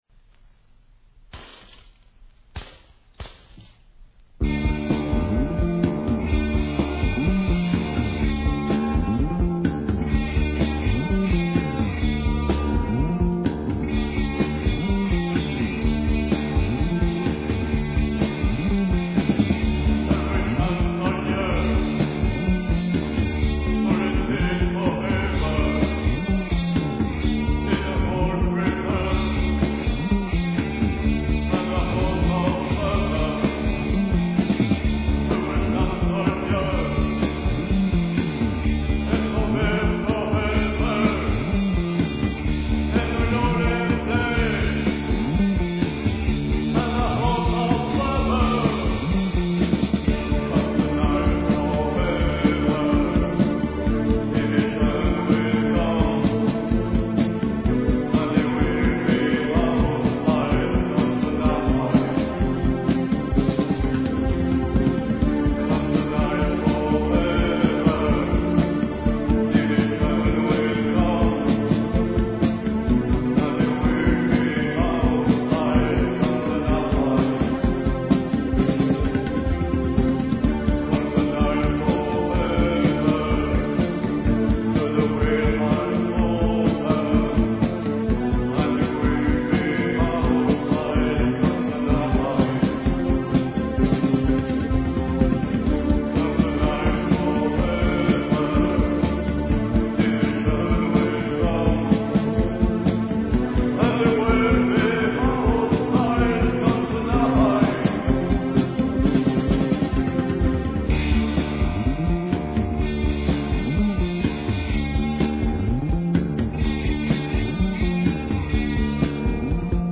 Cold wave Unique 45t